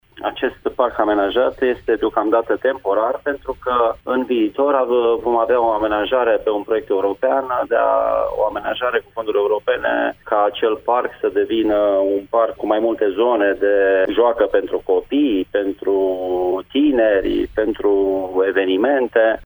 Însa, mai spune primarul, parcul destinat animalelor de companie, va fi mutat in viitorul apropiat intr-o alta zona: